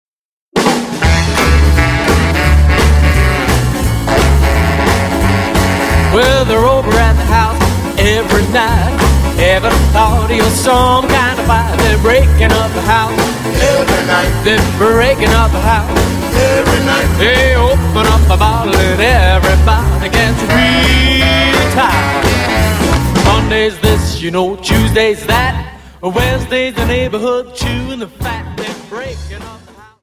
Dance: Jive Song